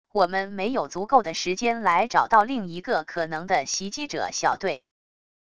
我们没有足够的时间来找到另一个可能的袭击者小队wav音频生成系统WAV Audio Player